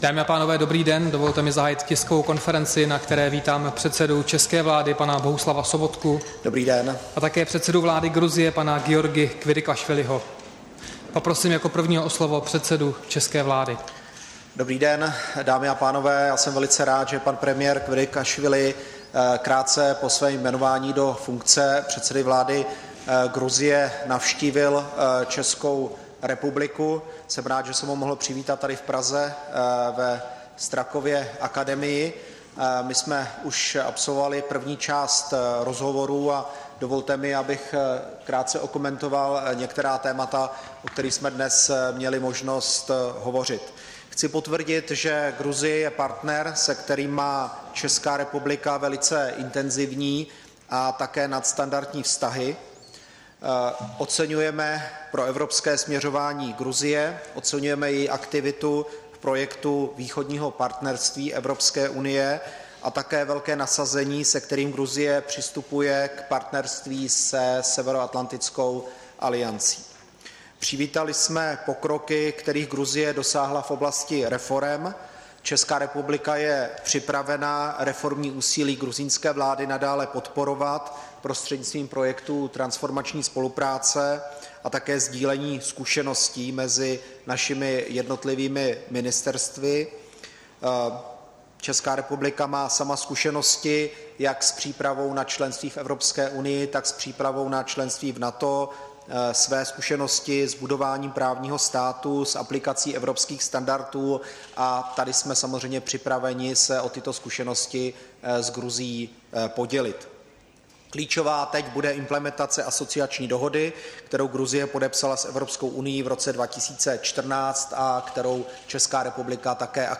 Tisková konference po setkání premiéra Bohuslava Sobotky s předsedou vlády Gruzie Giorgim Kvirikašvilim, 22. února 2016